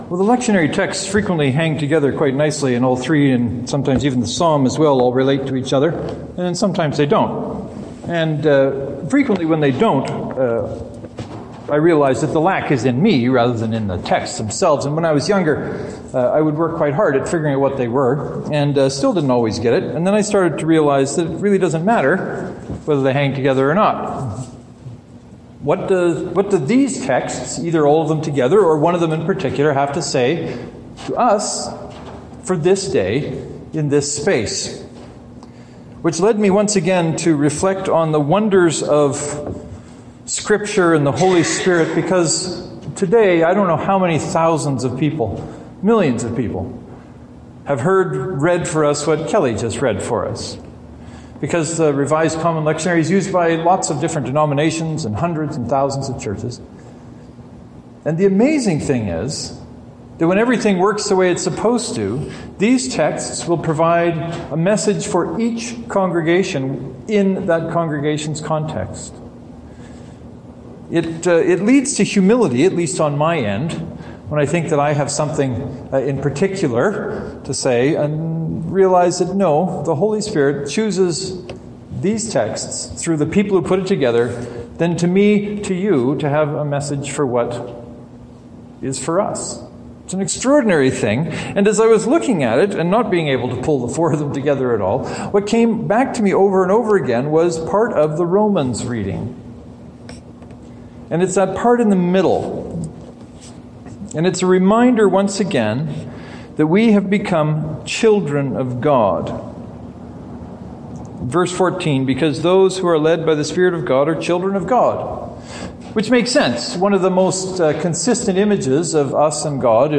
Knox Presbyterian God’s children (to download, right click and select “Save Link As .